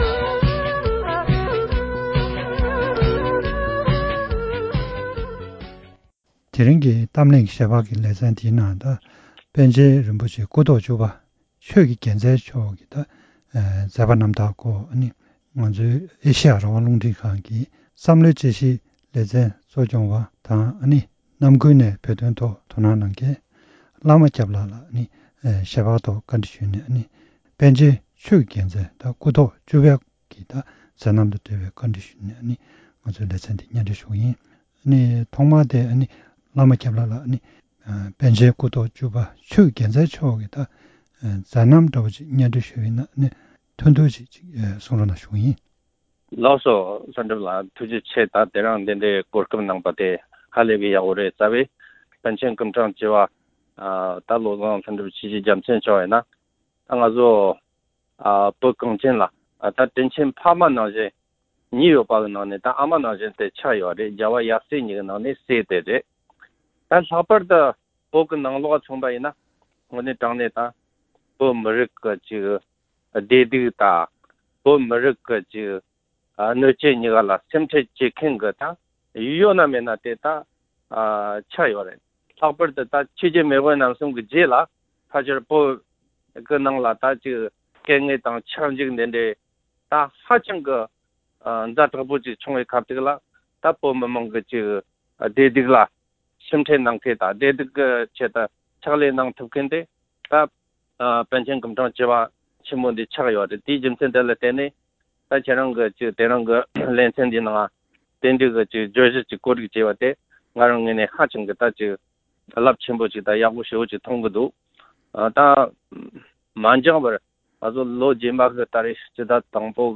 གླེང་མོལ།